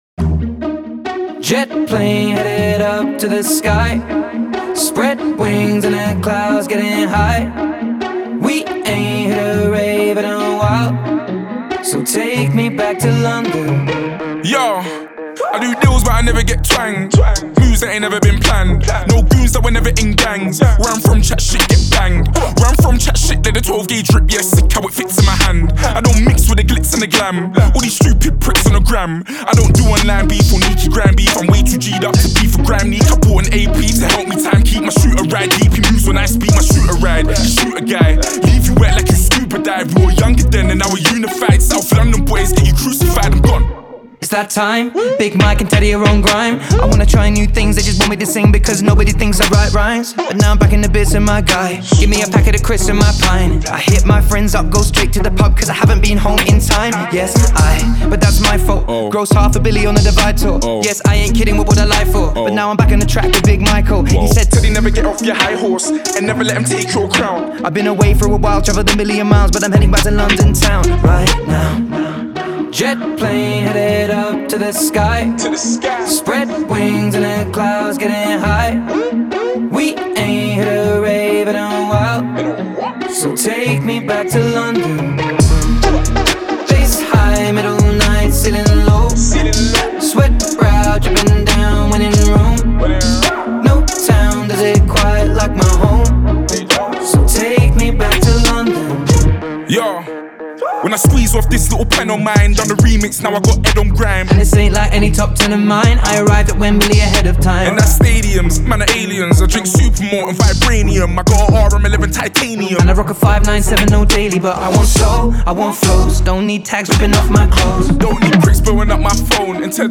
в стиле грайм